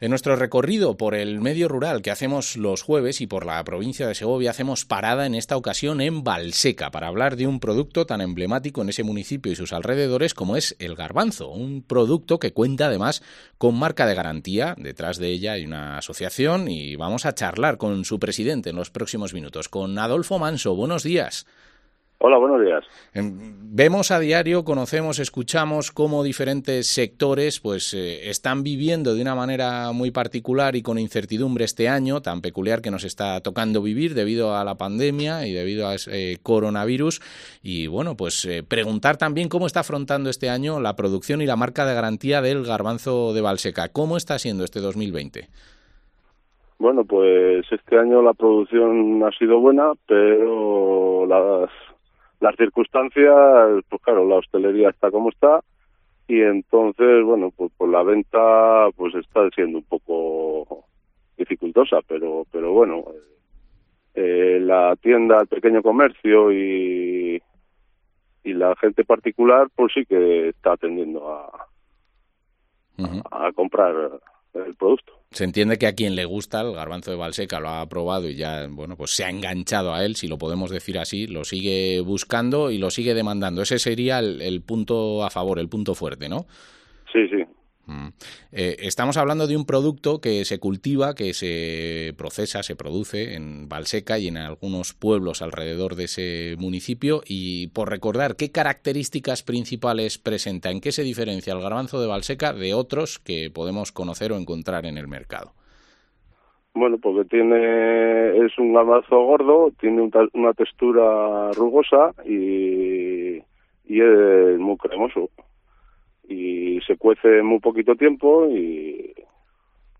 AgroCOPE Segovia Entrevista